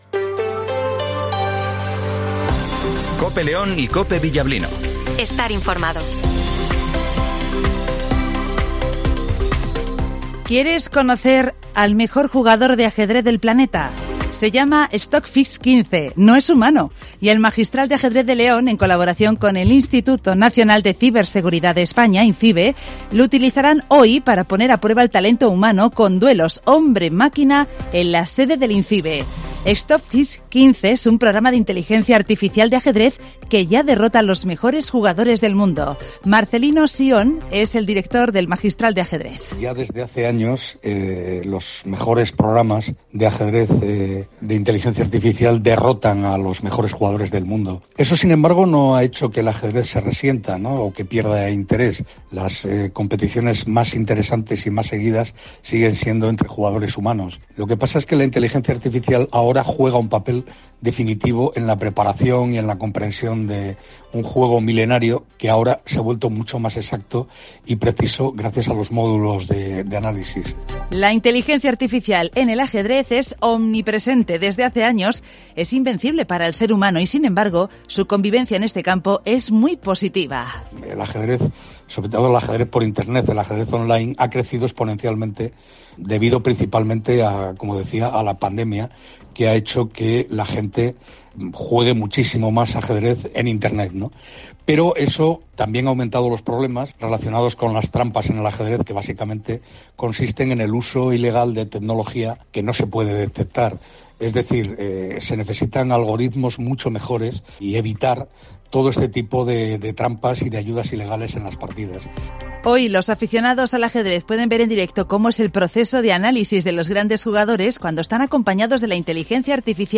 Redacción digital Madrid - Publicado el 02 jun 2023, 08:20 - Actualizado 02 jun 2023, 12:18 1 min lectura Descargar Facebook Twitter Whatsapp Telegram Enviar por email Copiar enlace - Informativo Matinal 08:20 h